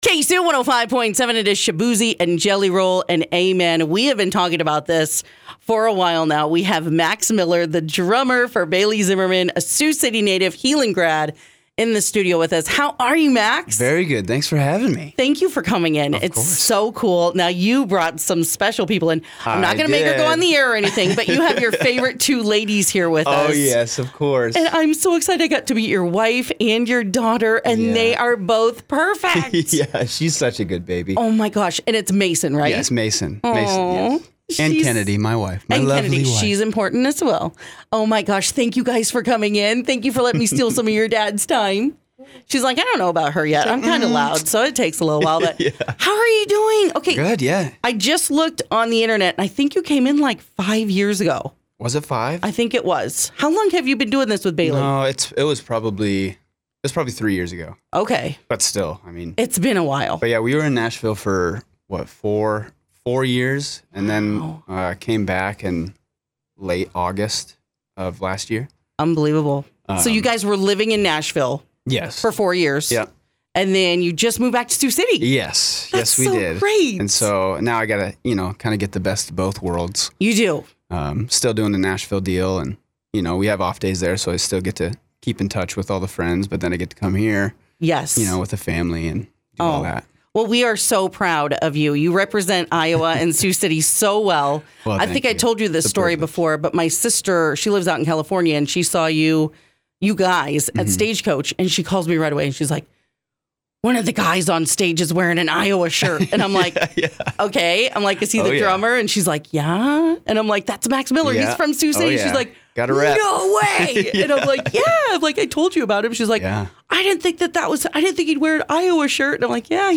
THE 2017 HEELAN GRAD STOPPED BY THE KSUX STUDIO TO TALK TOURING, FAMILY AND COUNTRY MUSIC…